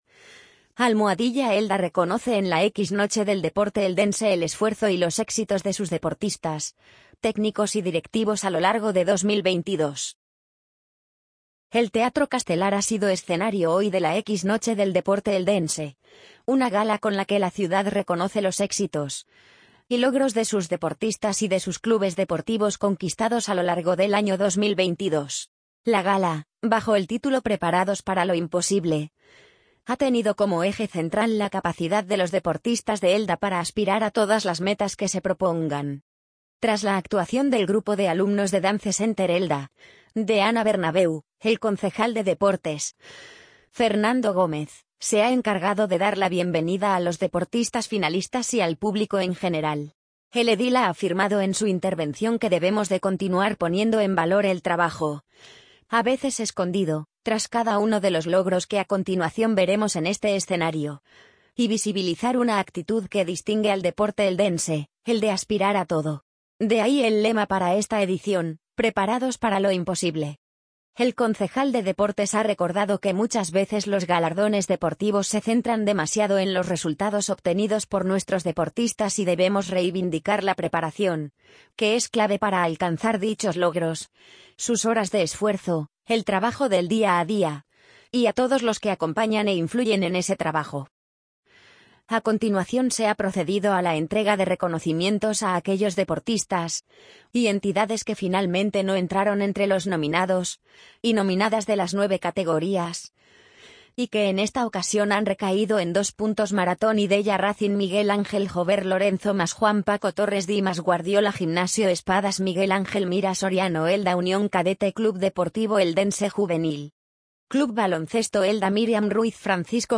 amazon_polly_63852.mp3